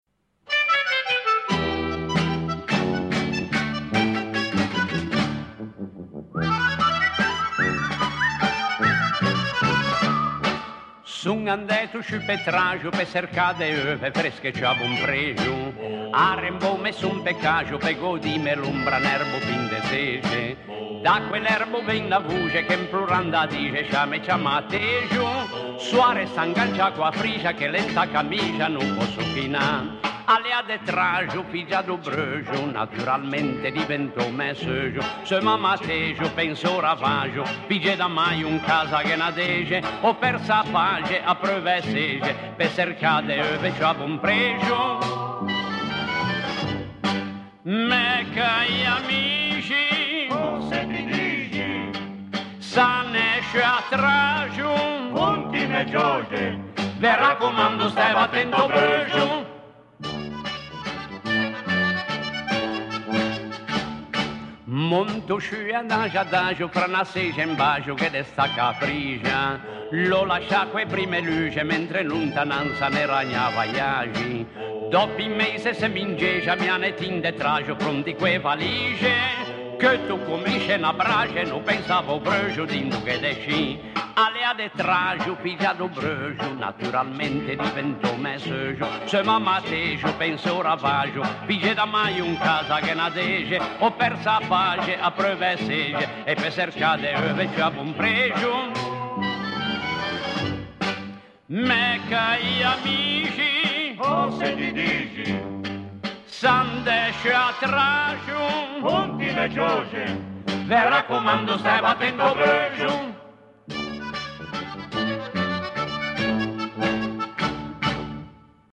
[ Cansoìn zenéixi ]